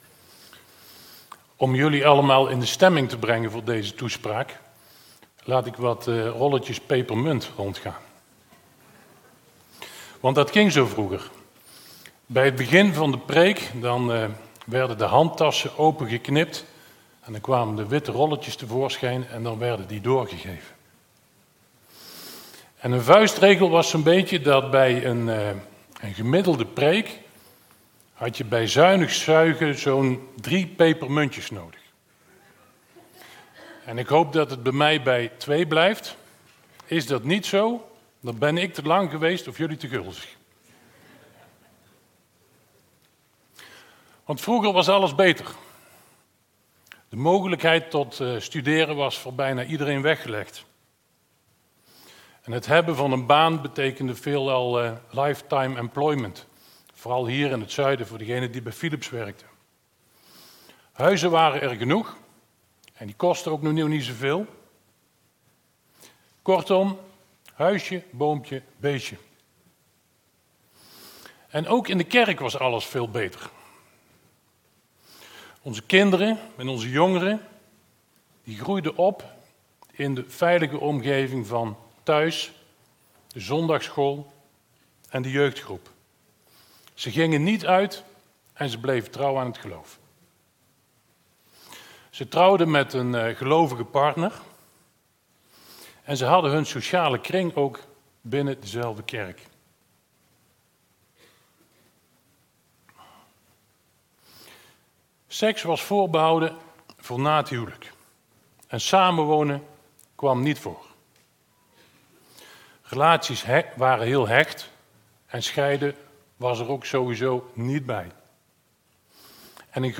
Toespraak 17 juli: 'Vroeger was alles beter' - De Bron Eindhoven
Ditmaal een wat levensbeschouwelijke toespraak aan de hand van het boek Spreuken over vroeger en nu.